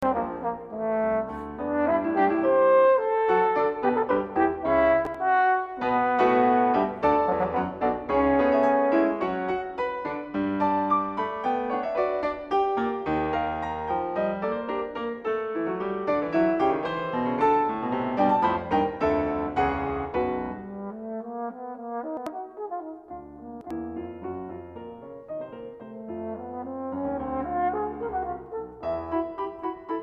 alto horn
piano